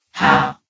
CitadelStationBot df15bbe0f0 [MIRROR] New & Fixed AI VOX Sound Files ( #6003 ) ...
how.ogg